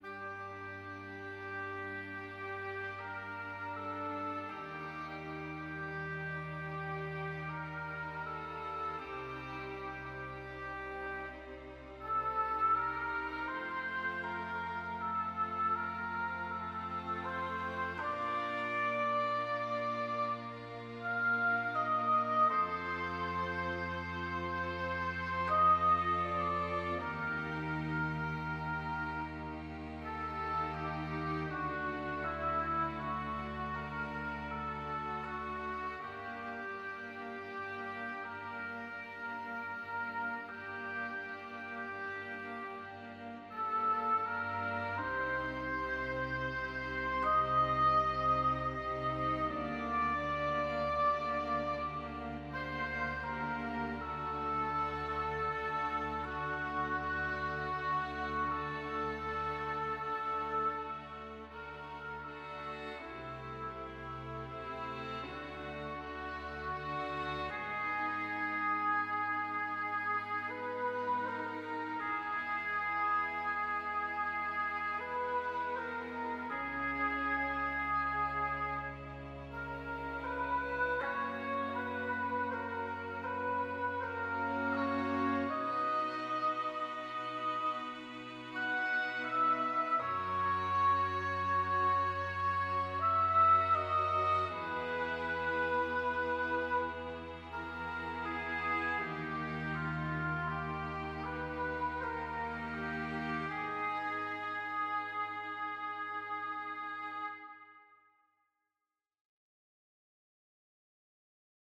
Seven varations for orchestra